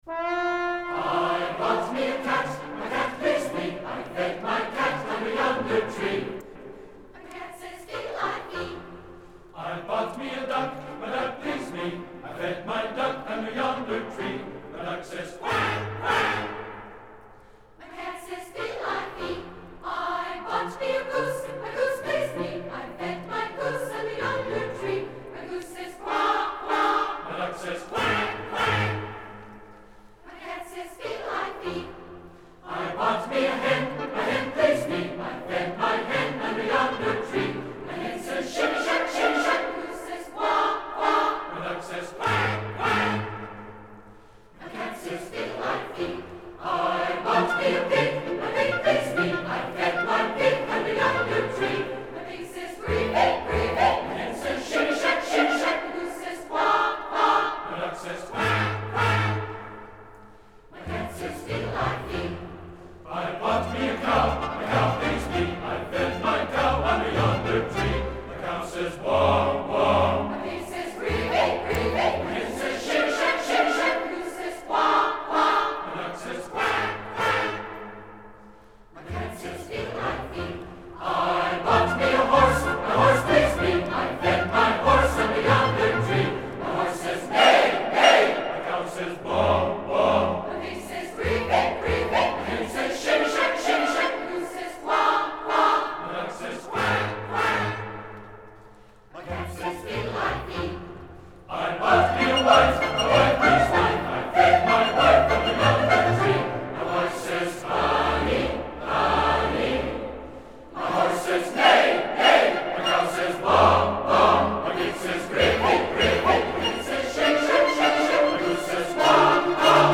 Música vocal